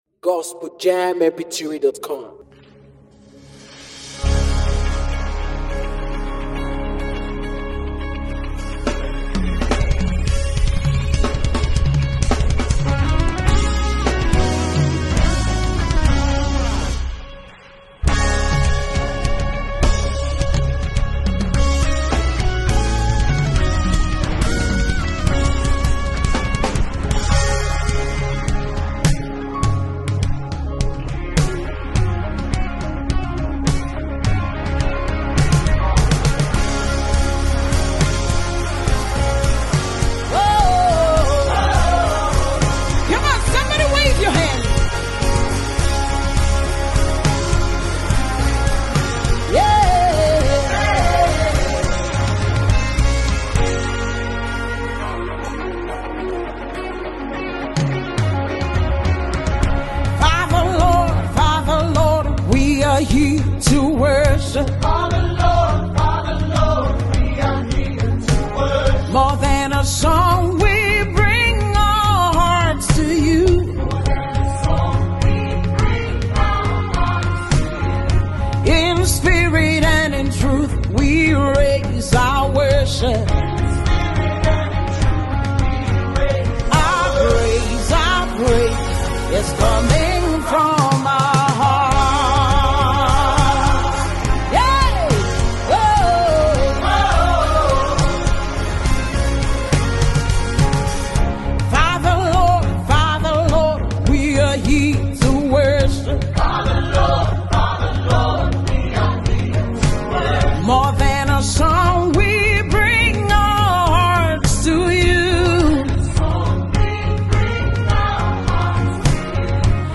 a new melodious sound